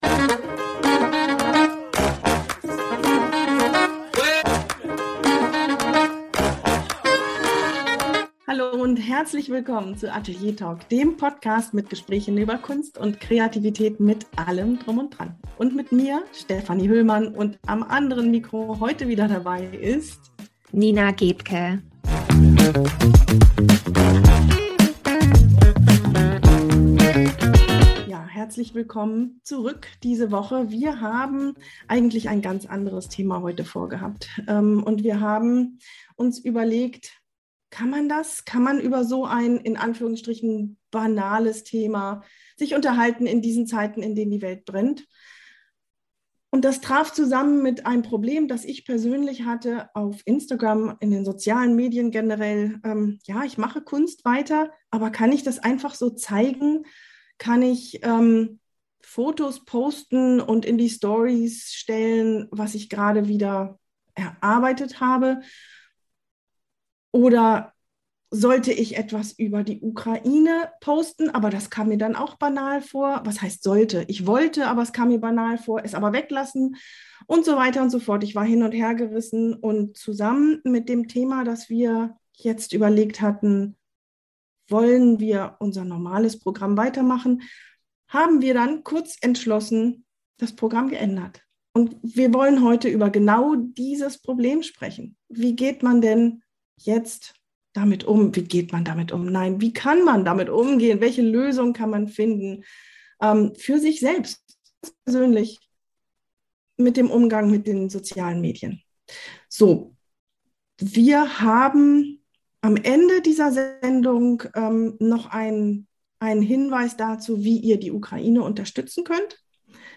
Ein nachdenkliches Gespräch